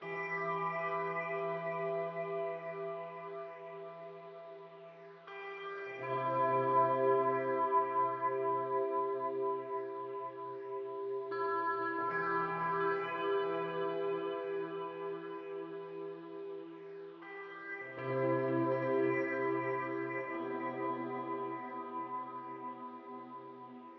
描述：E小调的后摇滚乐的吉他循环。
Tag: 80 bpm Rock Loops Guitar Electric Loops 4.04 MB wav Key : Unknown